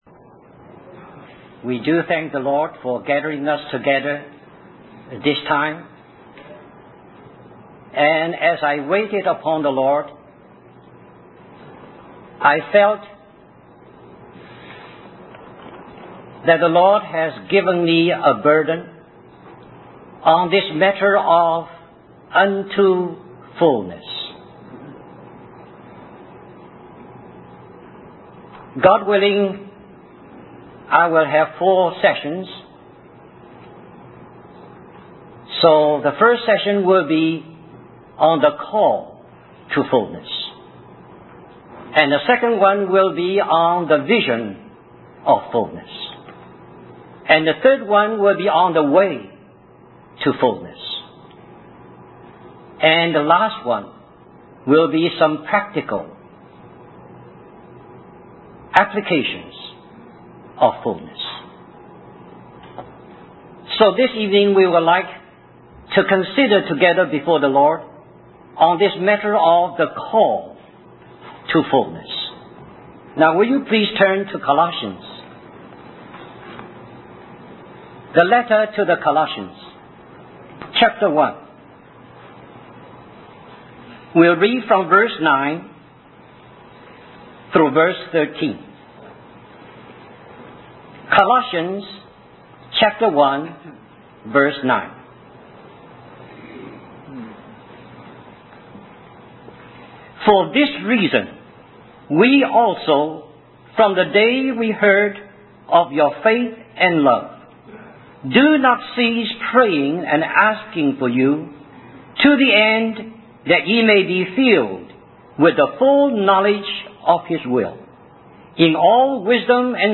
In this sermon, the speaker discusses the concept of fullness in relation to God.